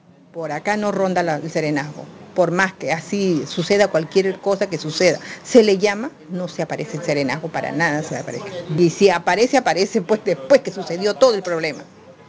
Con respecto al servicio de serenazgo, la vecina manifestó sentirse descontenta puesto que, de acuerdo a lo que considera, ninguna patrulla realiza rondas para brindar seguridad en las inmediaciones del parque Virgen de Las Mercedes.
AUDIO-03-POBLADORA.mp3